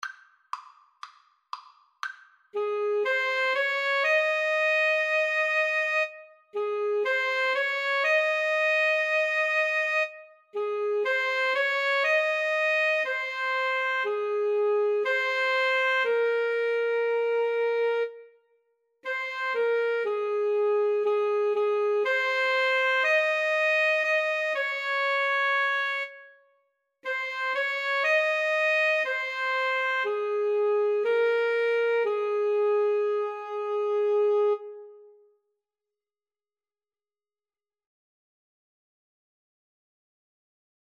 Vivo
Arrangement for Alto Saxophone Duet
Db major (Sounding Pitch) Ab major (French Horn in F) (View more Db major Music for Alto Saxophone Duet )